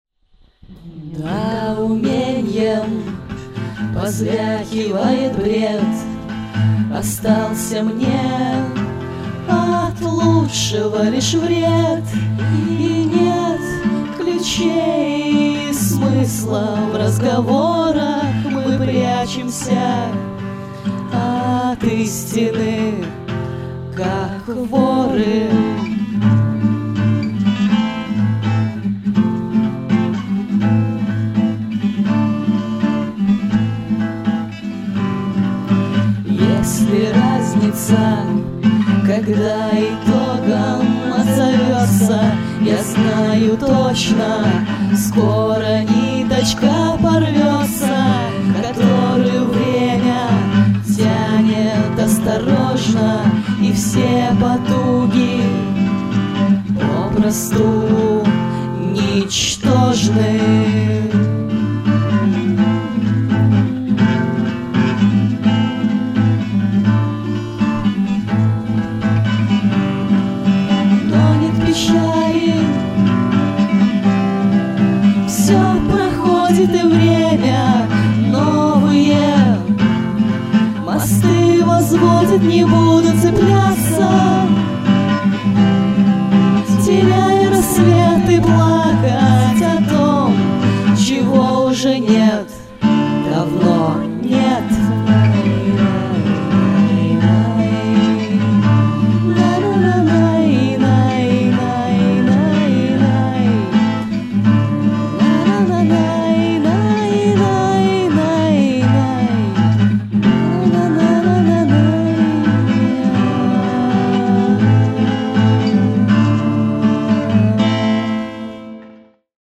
Концертная версия композиции